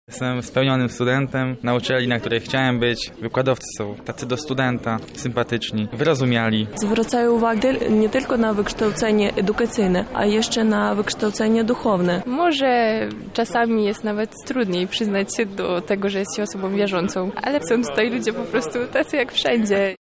Salę wypełnili studenci, którzy dzielą się wrażeniami z pierwszych tygodni na uniwersytecie